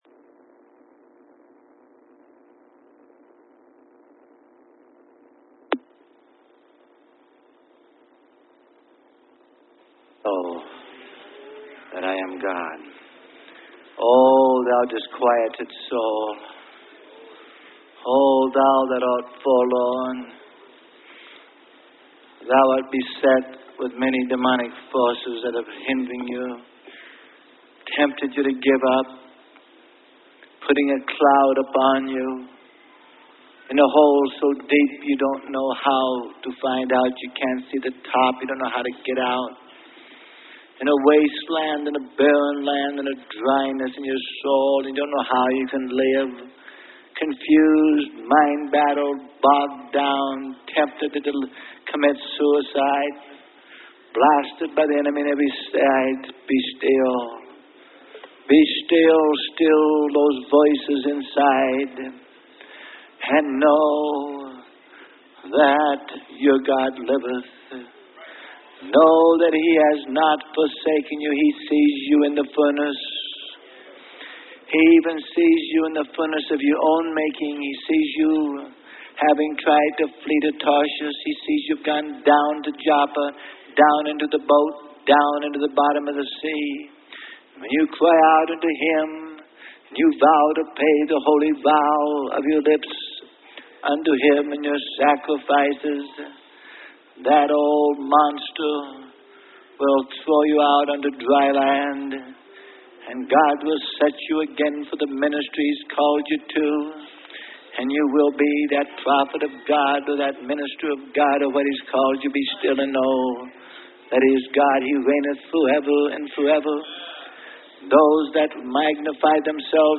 Sermon: The Demonic Underground - Freely Given Online Library